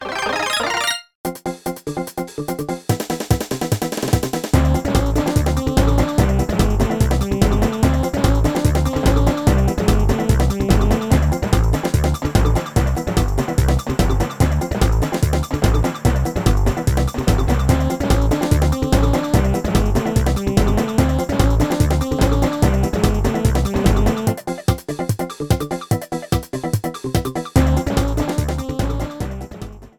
Shortened, fadeout